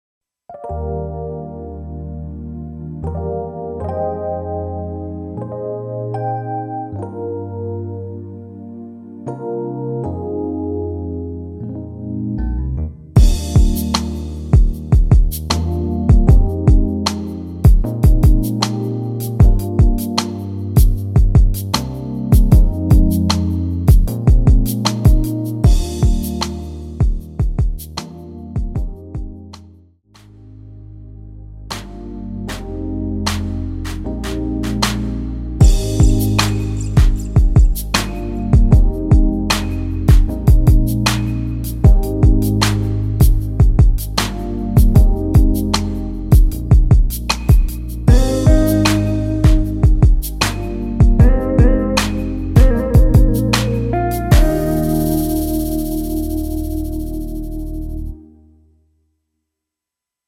엔딩이 너무 길어서 라이브 하시기 좋게 4마디로 편곡 하였습니다.(미리듣기 참조)
Eb
앞부분30초, 뒷부분30초씩 편집해서 올려 드리고 있습니다.
중간에 음이 끈어지고 다시 나오는 이유는